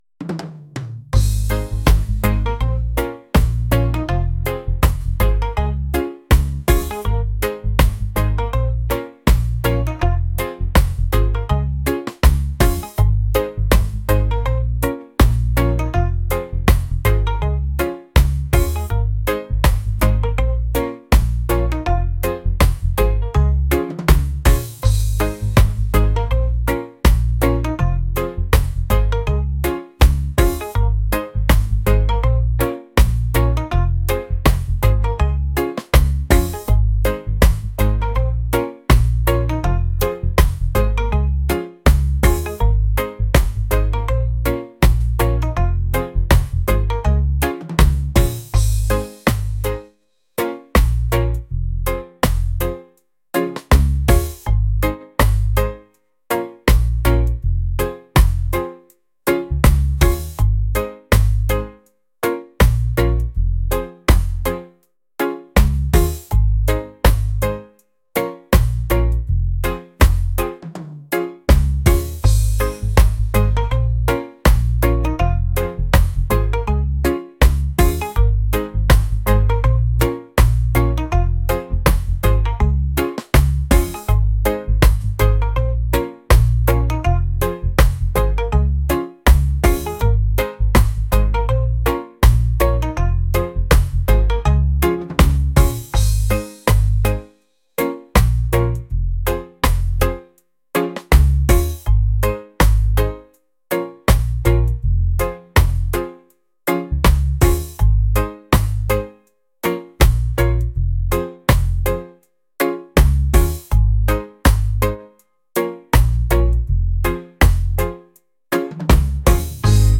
reggae | lofi & chill beats | lounge